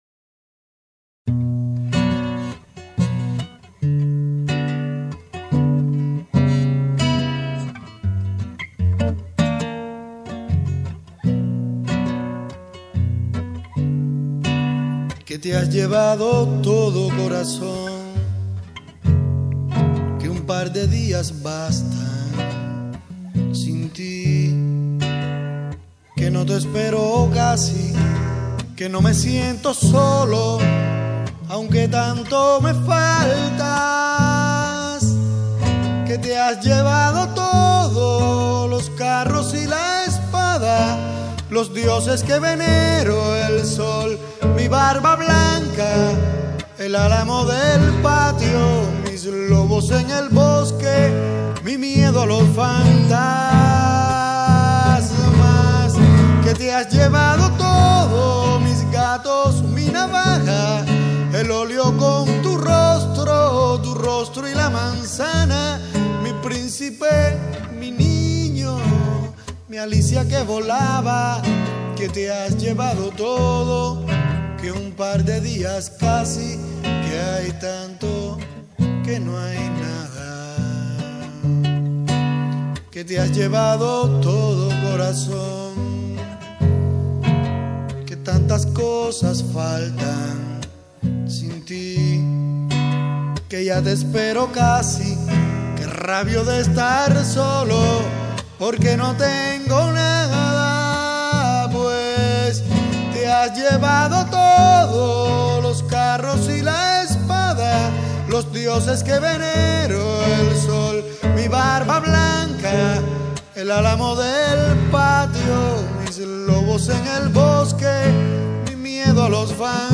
En 1994 grabé estas canciones en casa de un amigo en Cuba.